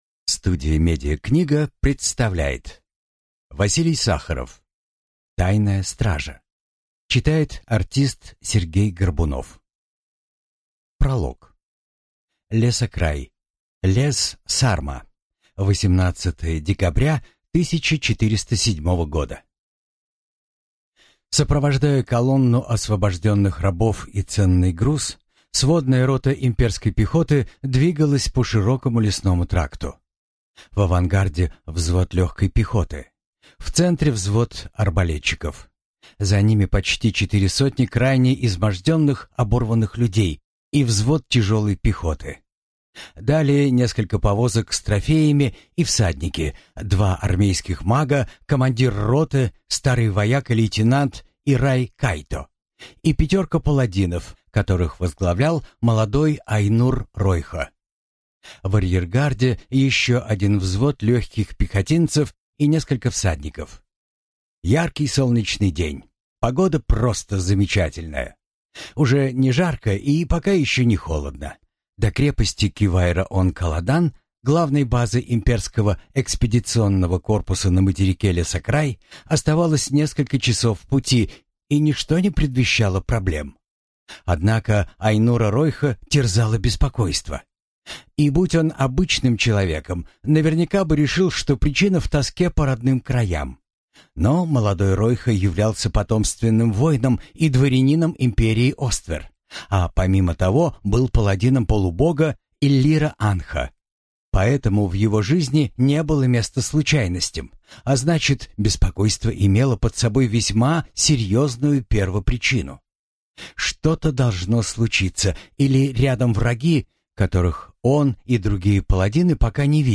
Aудиокнига Тайная стража